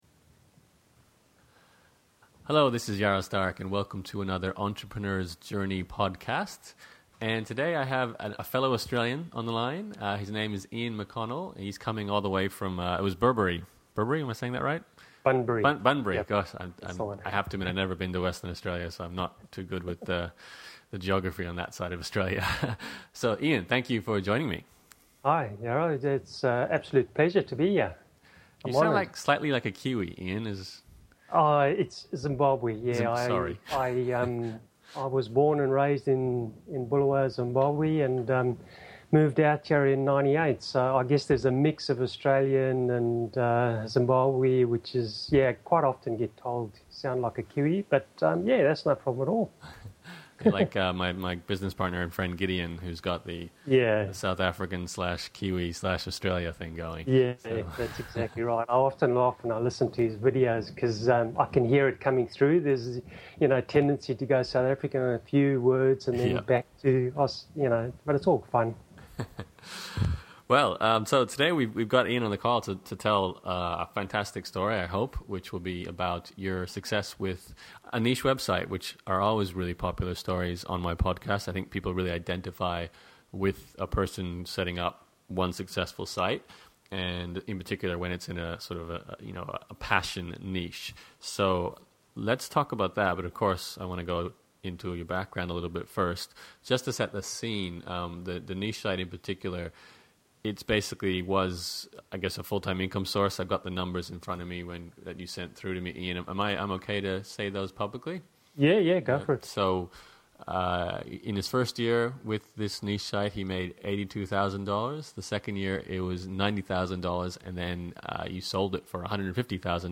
If you want some inspiration to create your own success story with a niche business, this is a must-listen-to interview.